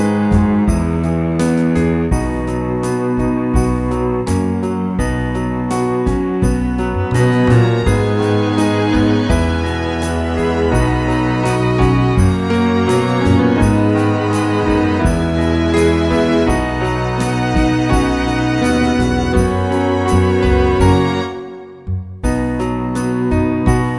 Up 2 Semitones For Female